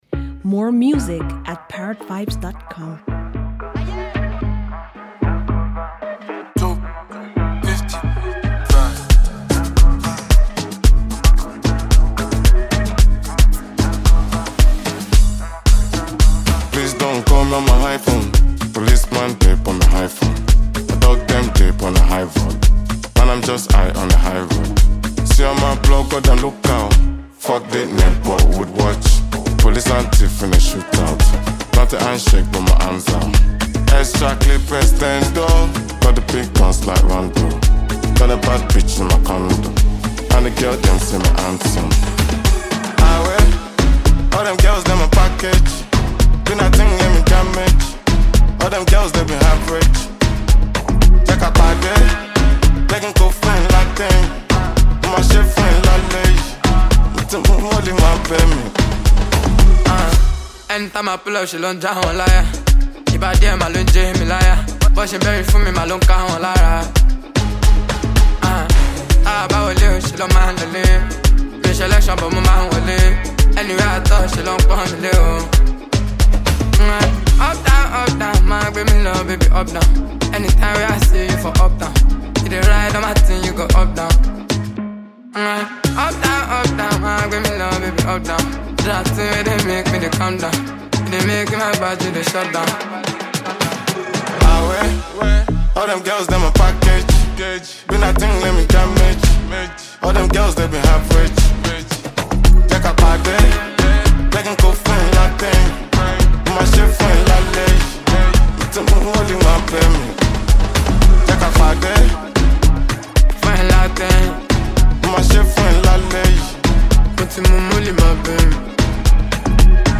incredibly skilled Nigerian singer